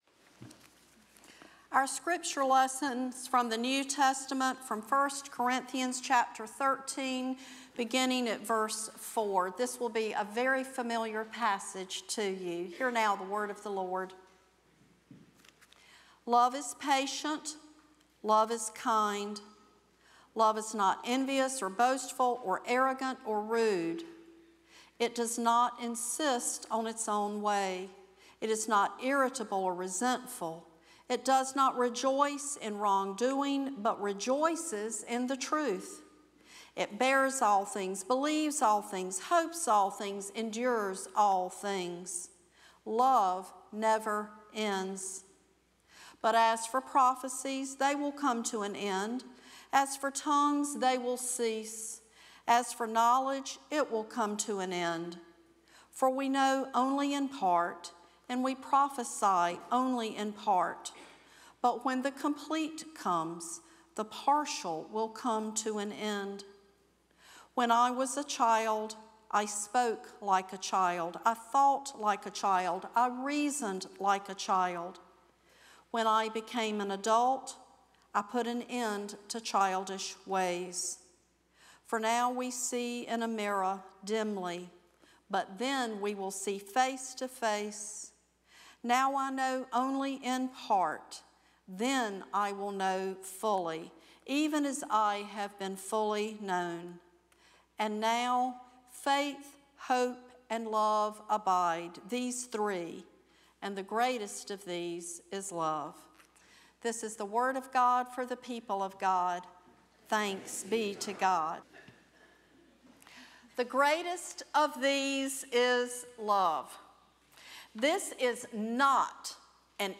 First Cary UMC's First Sanctuary Sermon "Love Never Ends&rdquo